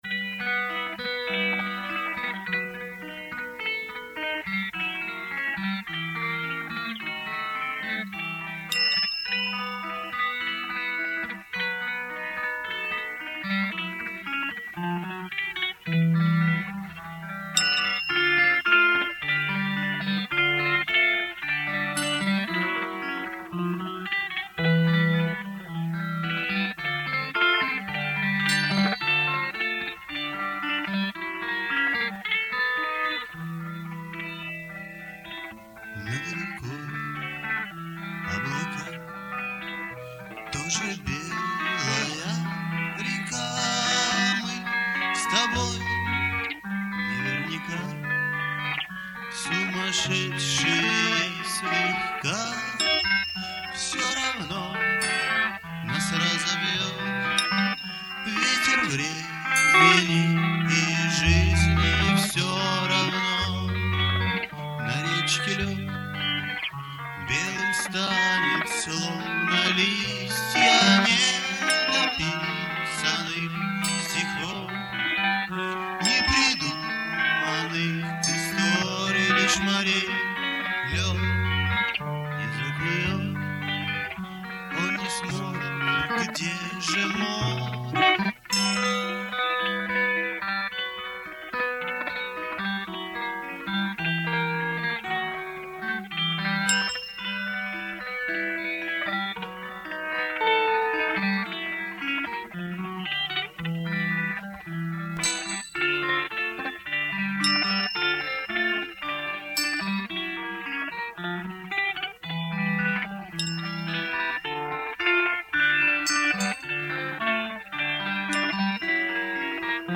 Семипалатинск, реставрированные записи - mp3.